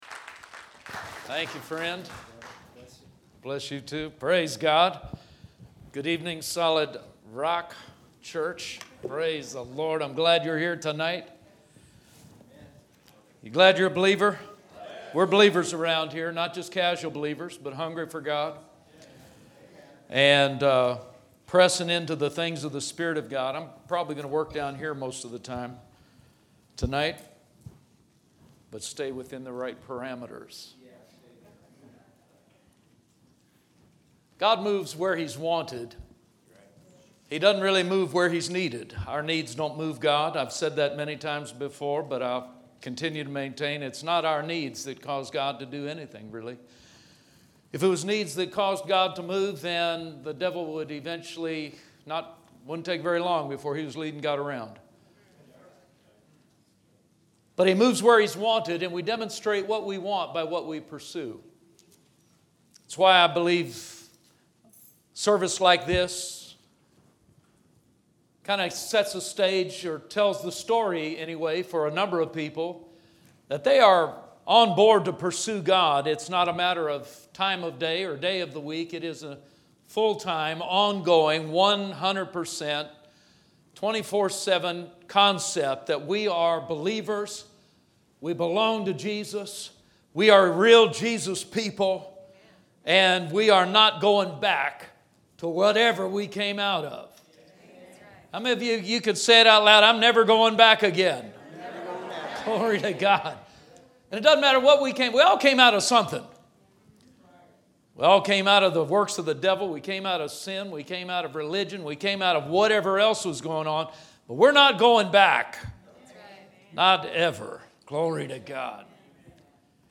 Sermon from Sunday night, June 13th, 2021.